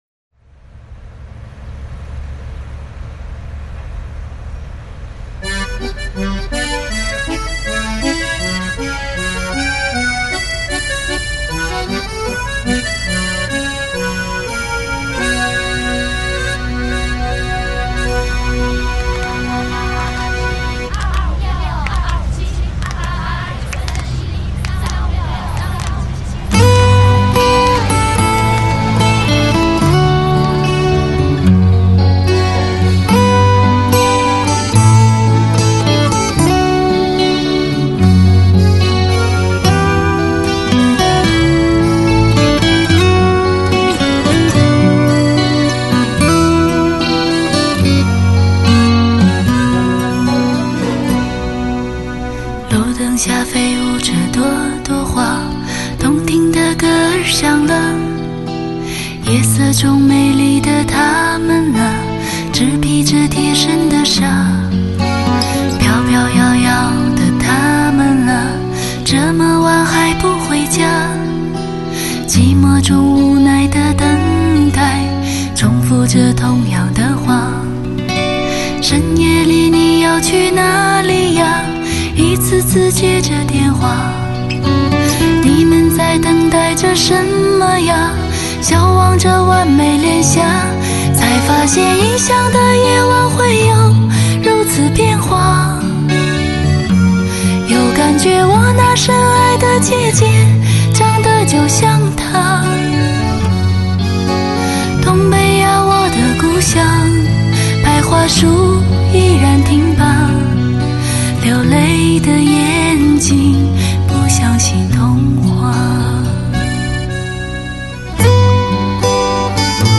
演绎上很有力度和气势
没想到这么瘦弱的小姑娘有这么强的爆发力